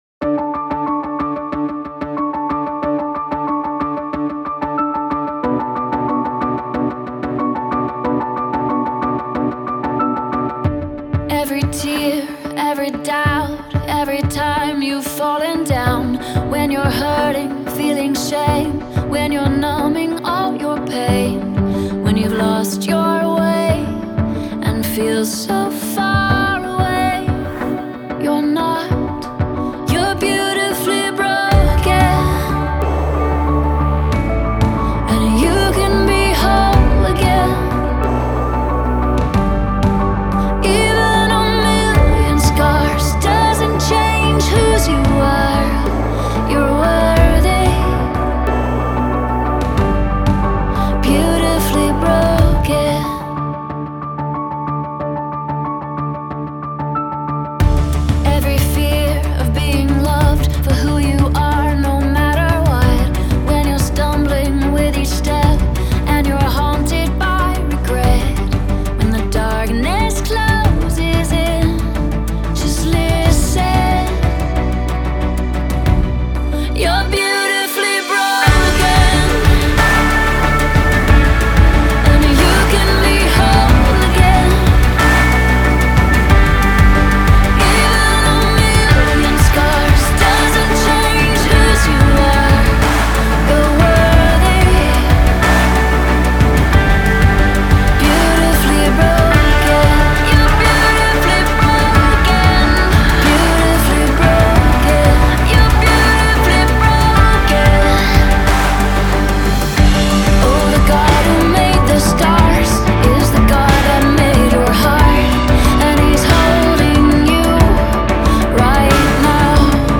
выполненная в жанре поп-рок.